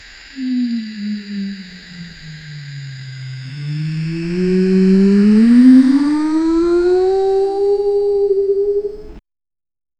The sound of the body swaying forward and backward before a jump, accompanied by a drawn-out girl “hey~~~” vocalization indicating a buildup of energy.
the-sound-of-the-body-aeyf2fwr.wav